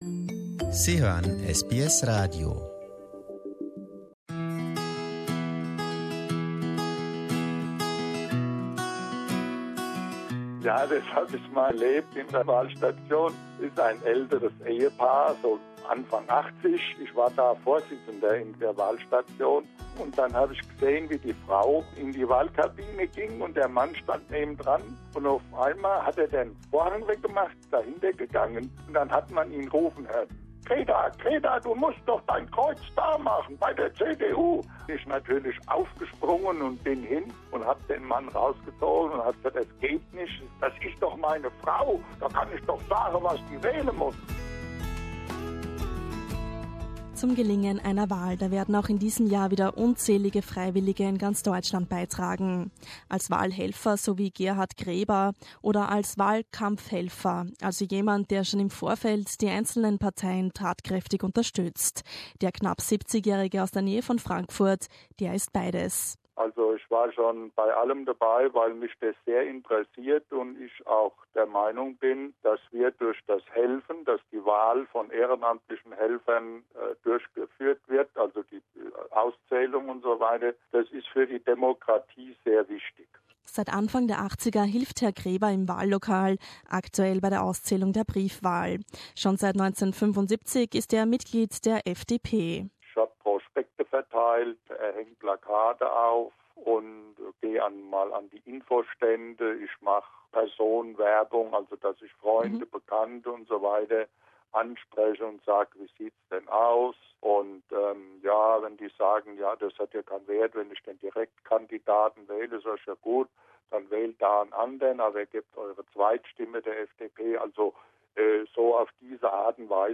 SBS German hat fünf der vielen namenlosen Ehrenamtlichen befragt.